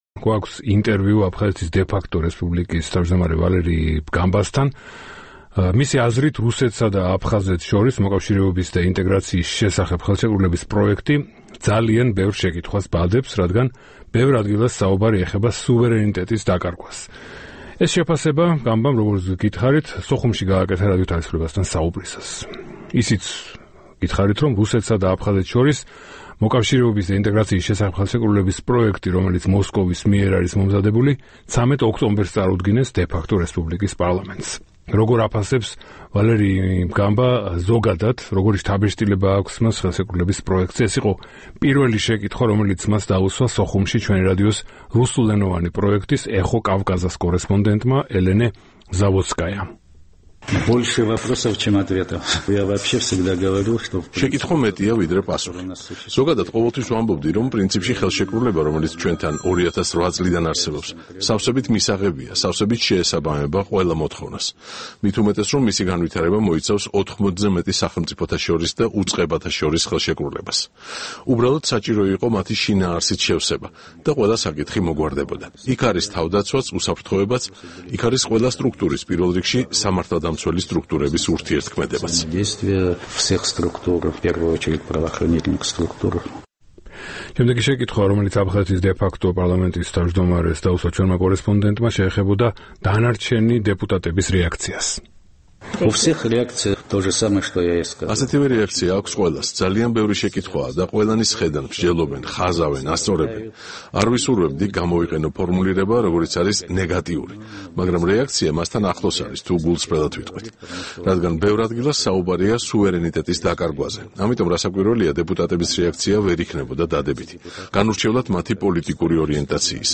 აფხაზეთის სუვერენიტეტს საფრთხე ემუქრება. ინტერვიუ ვალერი ბგანბასთან